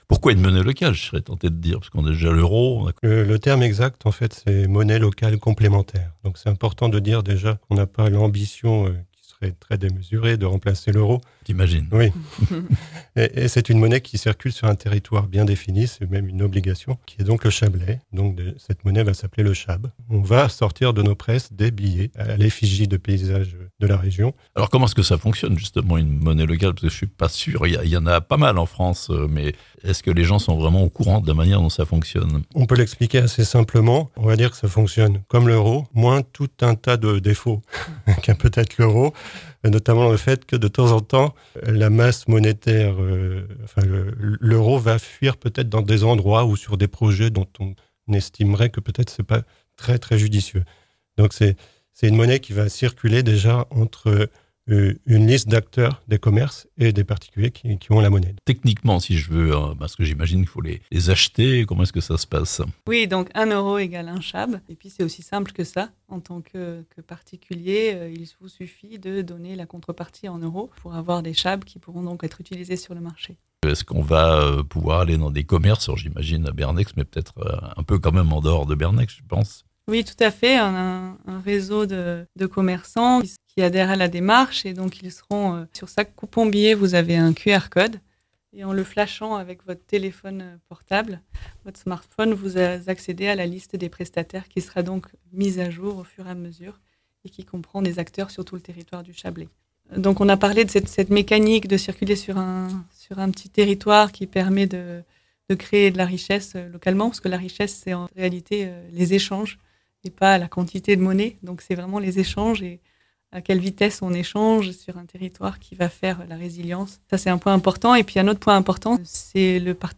Le Chab, une monnaie pour le Chablais (interviews)